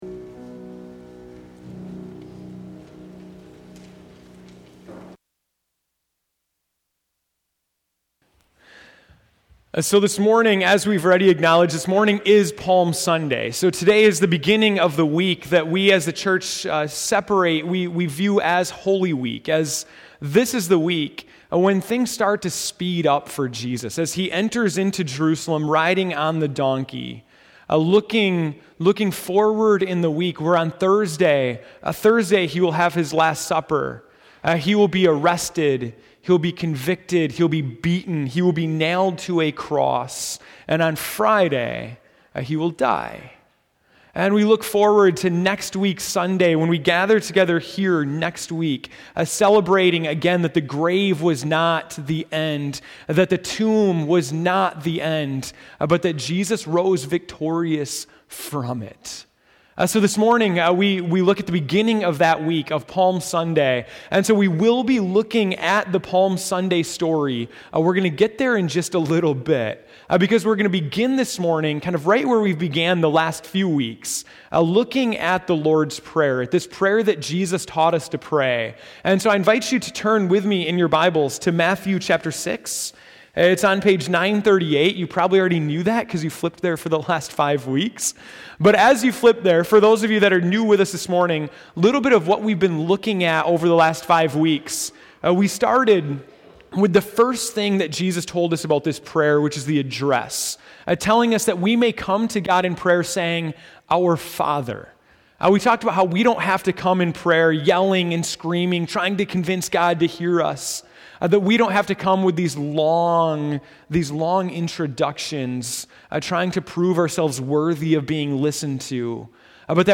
March 29, 2015 (Morning Worship)